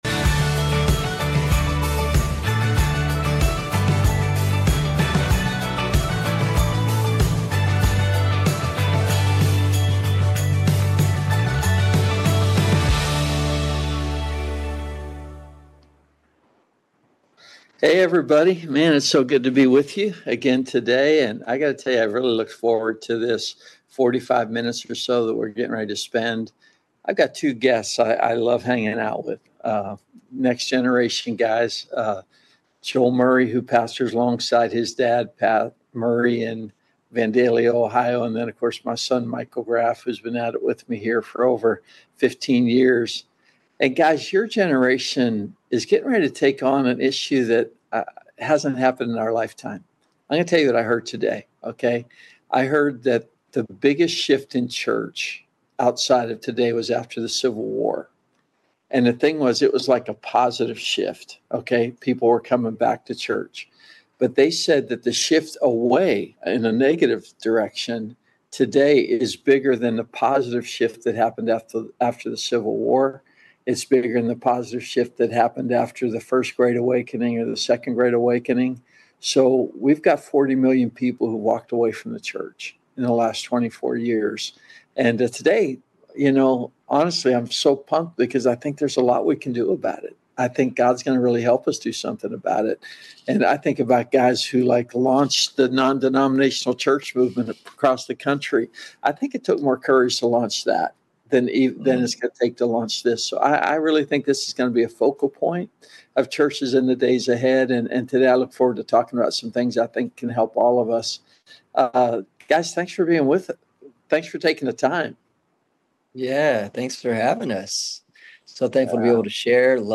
Webinar: Advancing Your Church by Being Attractive to the Generations - Significant Church Network